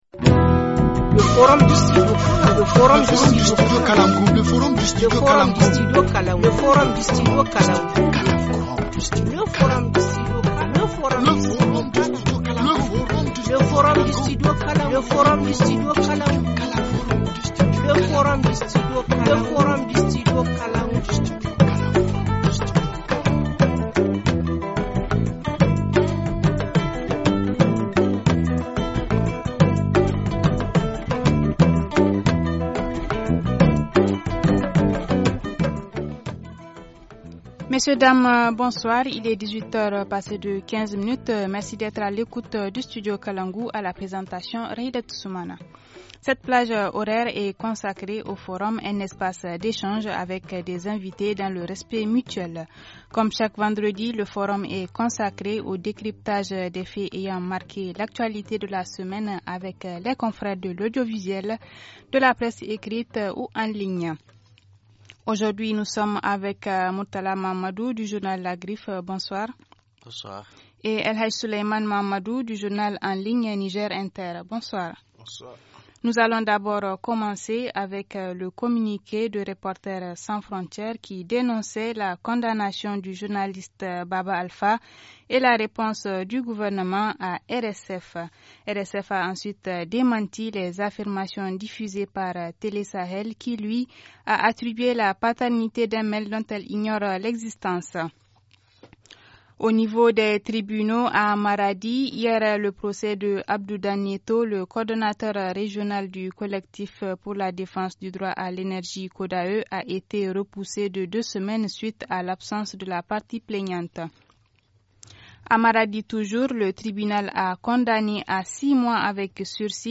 Comme chaque vendredi, le forum est consacré au décryptage des faits ayant marqués l’actualité de la semaine avec les confrères de l’audiovisuel, de la presse écrite ou en ligne.